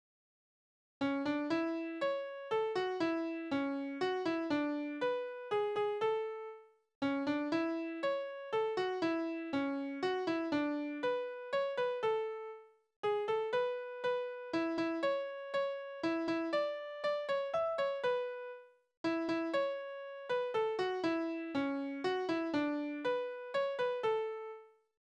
Liebeslieder: Die treue Rosa
Tonart: A-Dur
Taktart: 3/4
Tonumfang: Oktave